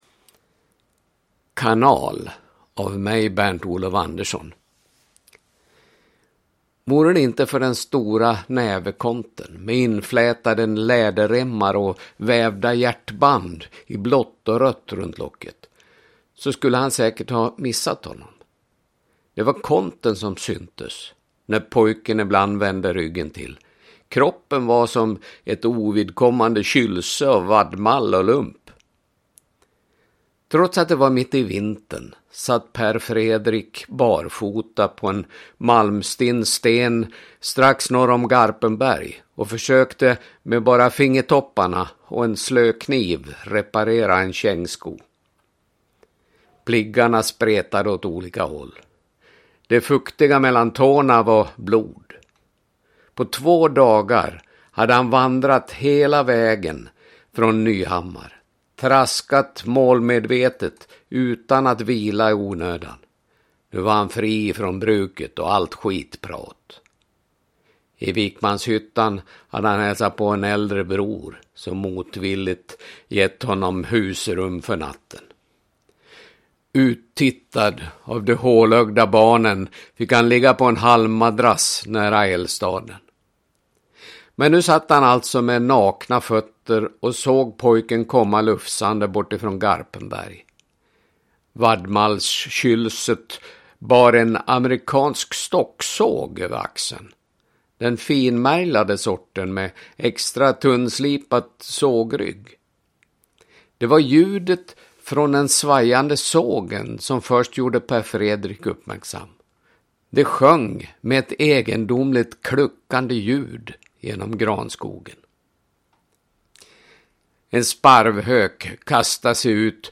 Kanal – Ljudbok – Laddas ner